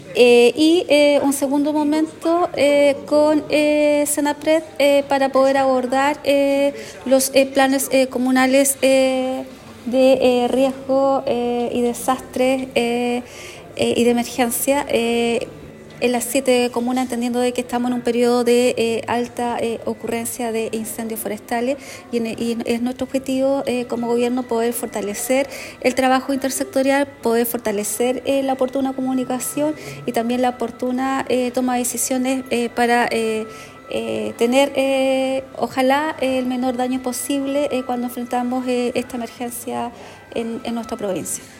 En el ámbito de la prevención de incendios forestales, otro de los temas centrales de la jornada, la delegada señaló que se abordaron los planes de emergencia de las siete comunas de la provincia.